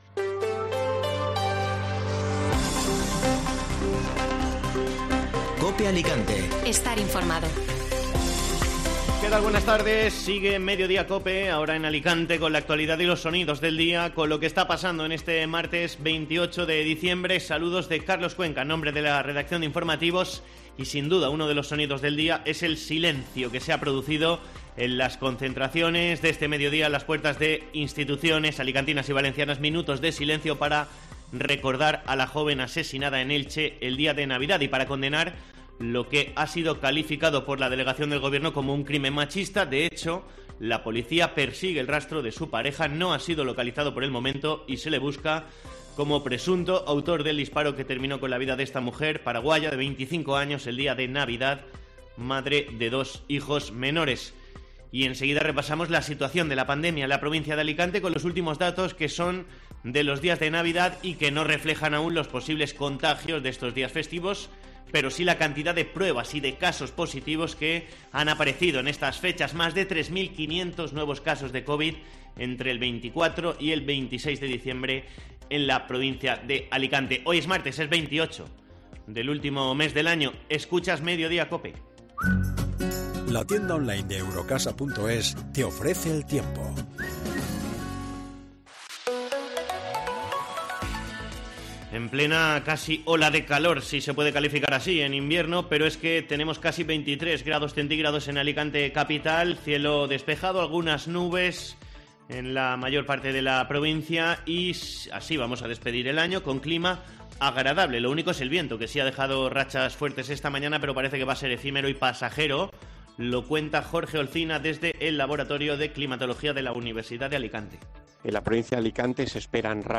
Informativo Mediodía COPE (Martes 28 de diciembre)